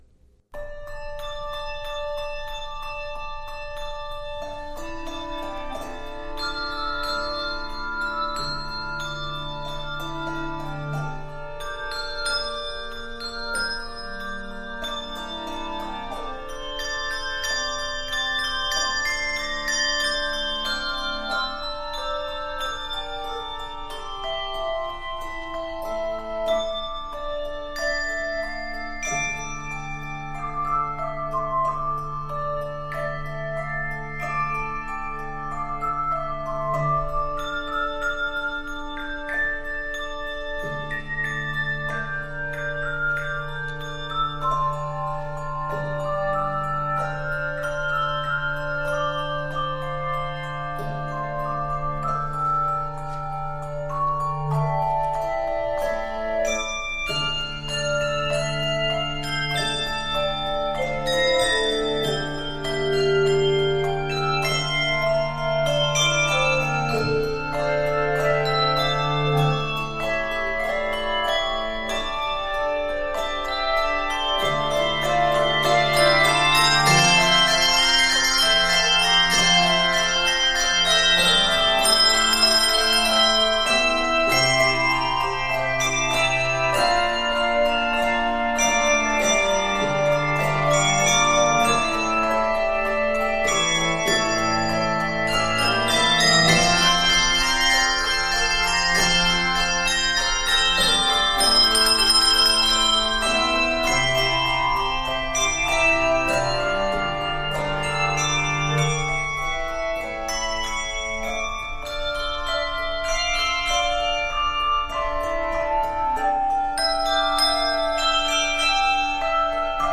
Key of D Minor.
Octaves: 3-5